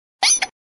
Cri de Psystigri dans Pokémon X et Y.